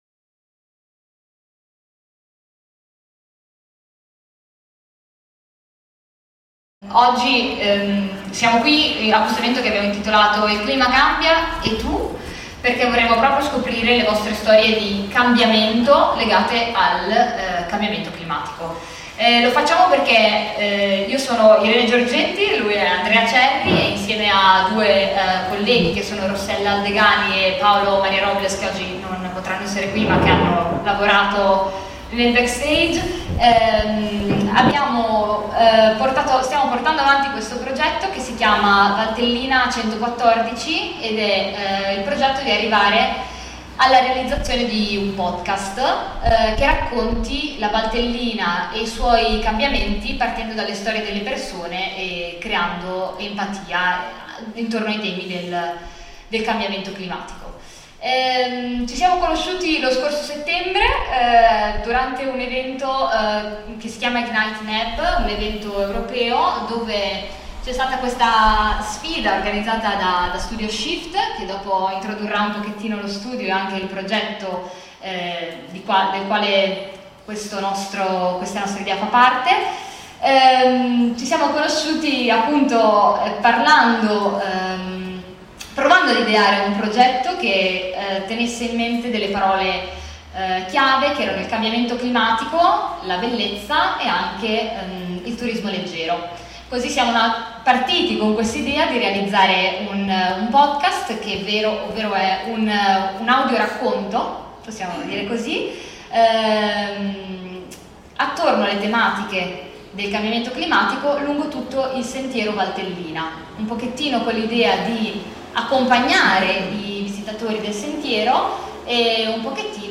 sala conferenze della CASA DELLA CULTURA di TALAMONA
Valtellina114-Conferenza.mp3